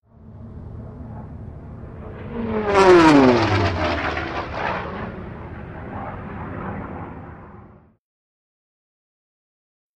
Japanese Zero|Bys
Airplane Japanese Zero By Fast Speed Close To Medium Perspective